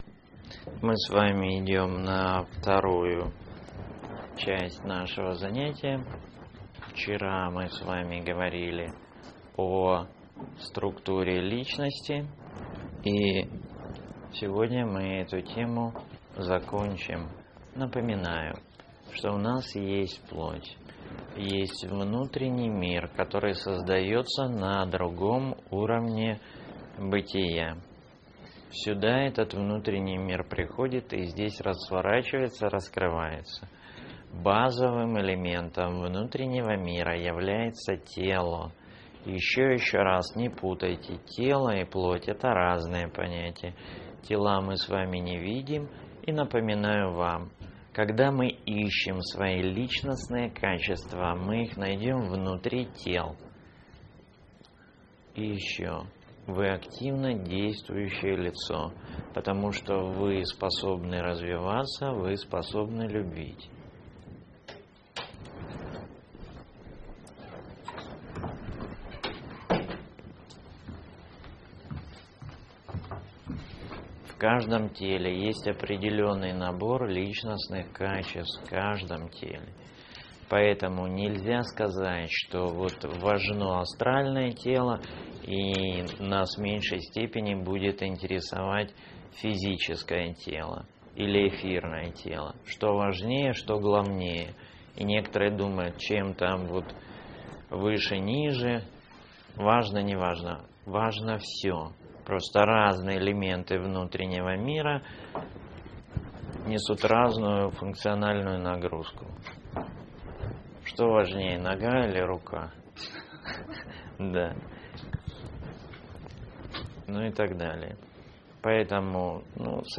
Лекции Семинар